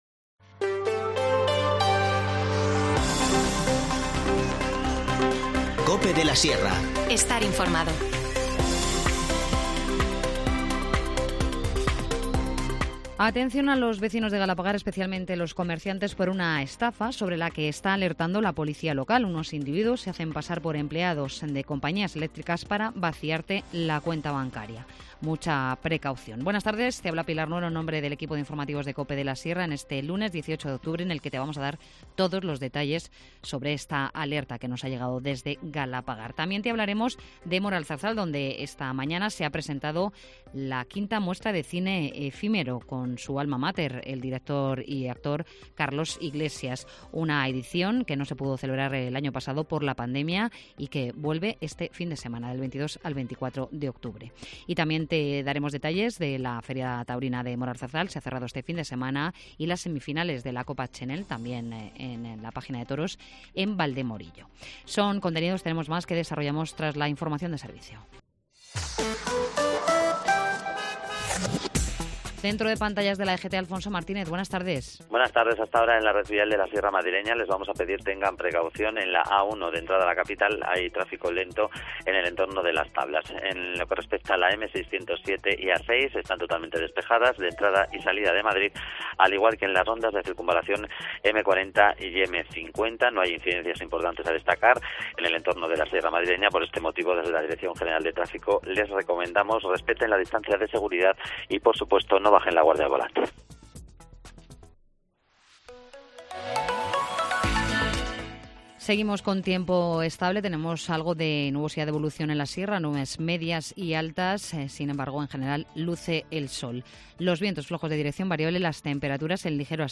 Informativo Mediodía 18 octubre